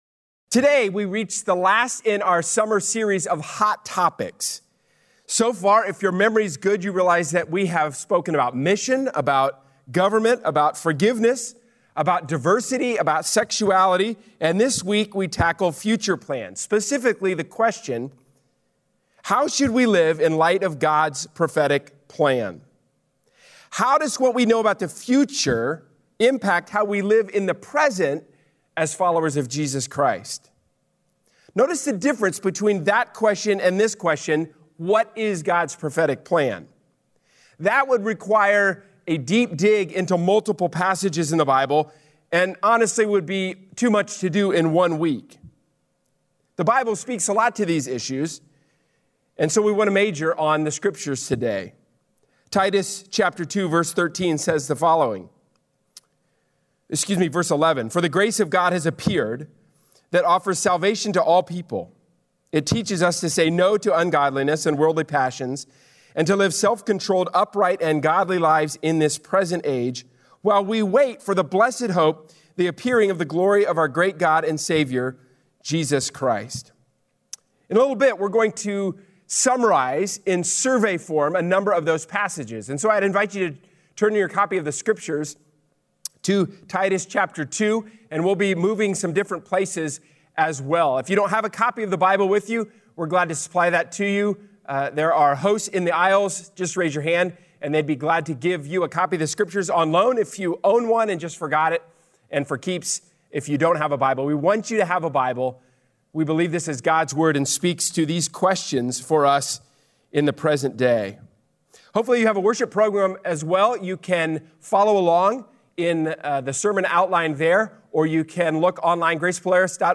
A sermon from the series "Hot Topics."